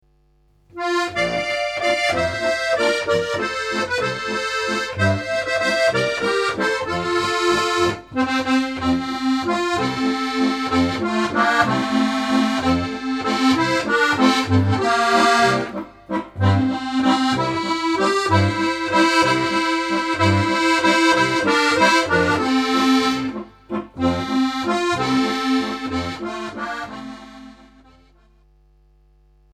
Besetzung: Steirische Harmonika